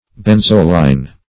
Search Result for " benzoline" : The Collaborative International Dictionary of English v.0.48: Benzoline \Ben"zo*line\, n. (Chem.)